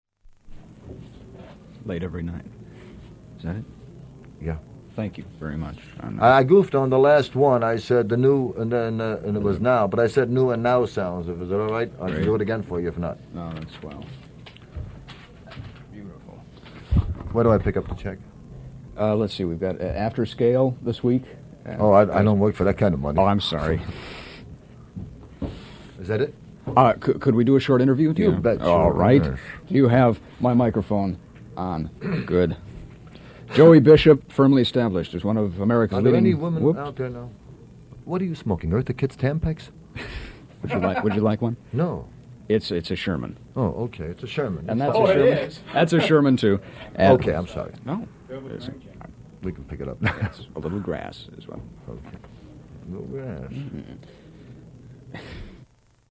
Tags: Armed Forces Radio Vietnam Bloopers AFVN Vietnam war Armed Forces Radio Vietnam Vietnam War Radio